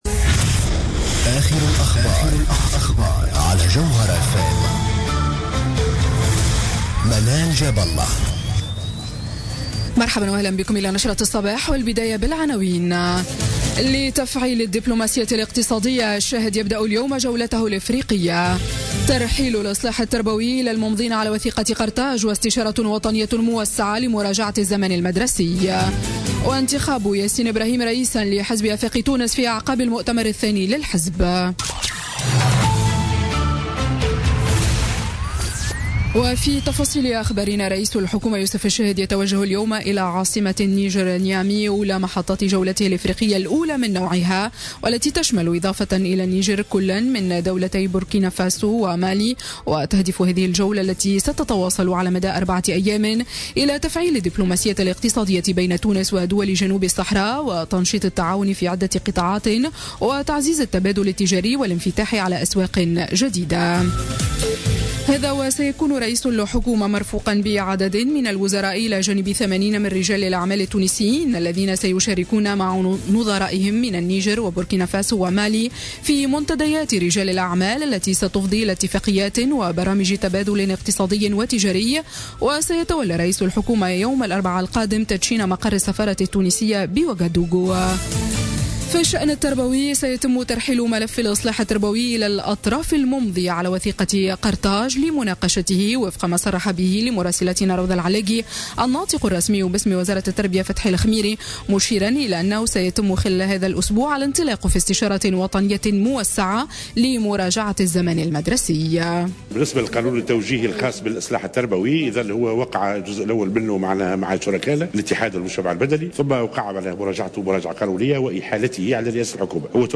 نشرة أخبار السابعة صباحا ليوم الإثنين 3 أفريل 2017